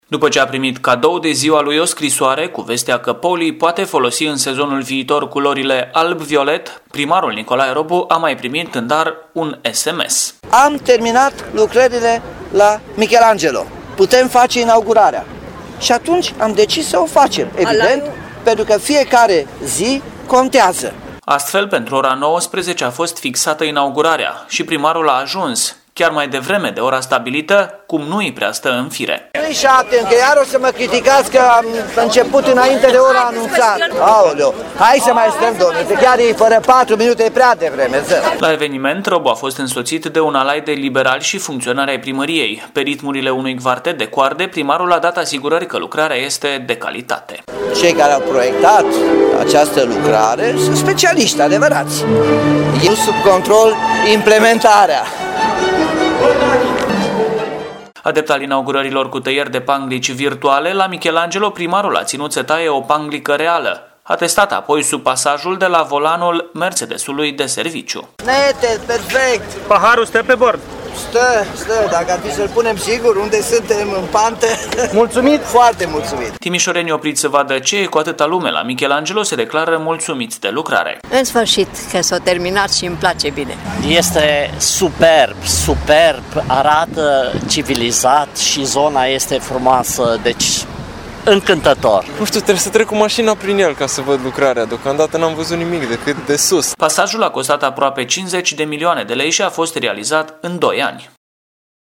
Momentul festiv a fost deschis de Incanto Quartetto care i-au întâmpinat cu ritmuri clasice pe cei prezenți la inagurarea festivă.